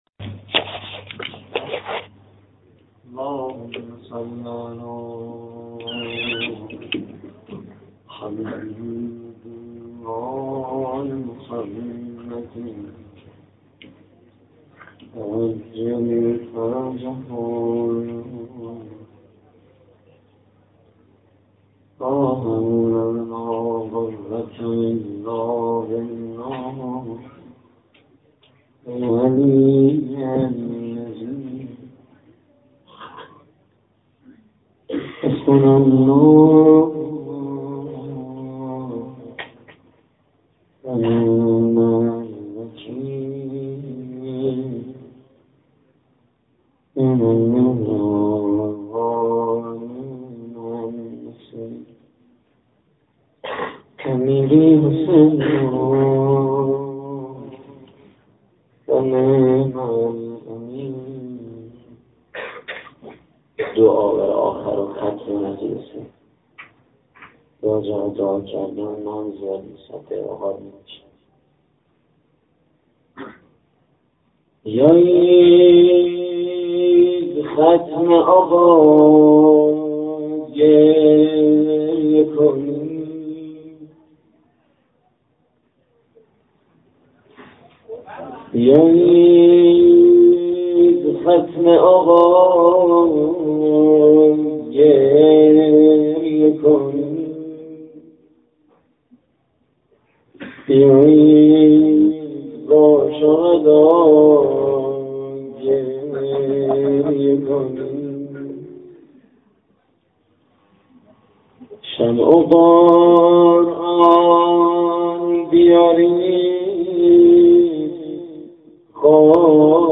روضه: بیایید ختم آقا گریه کنید
مراسم عزاداری اربعین حسینی / دبیرستان شهید بهشتی - شهرری